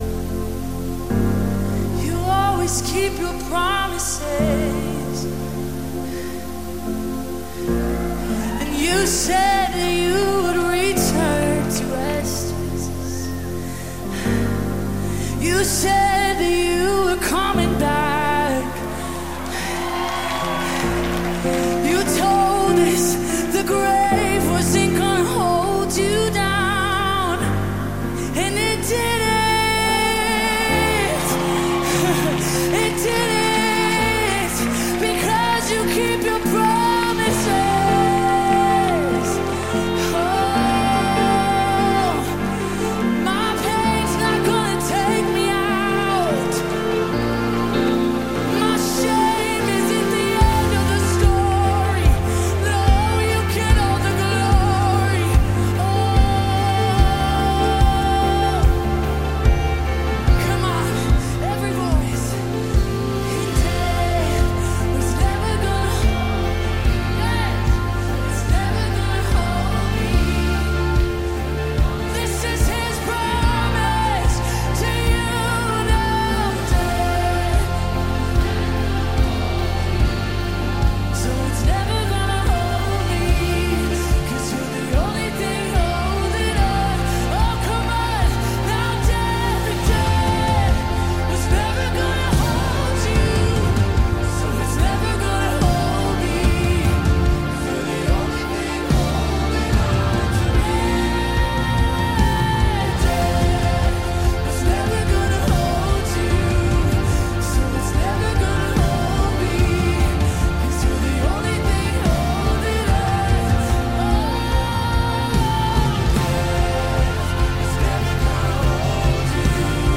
A Series of Sunday School Teachings